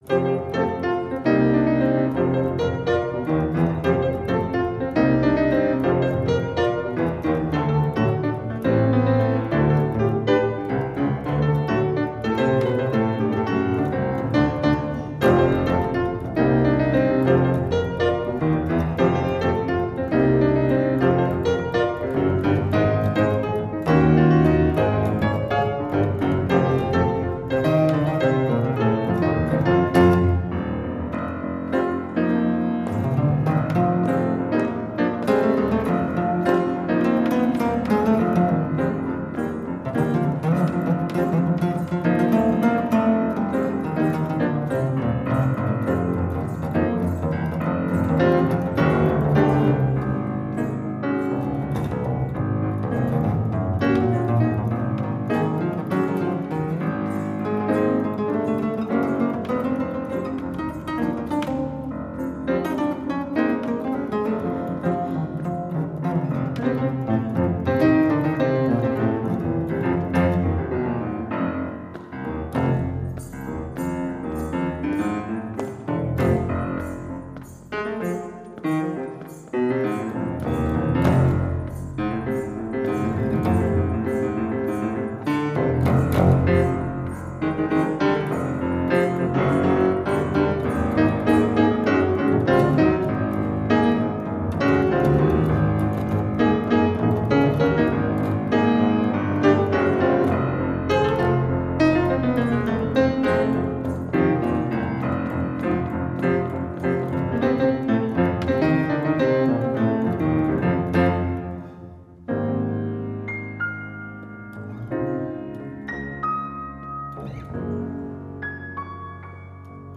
Piano
Gitarre
live in der Frauenkirche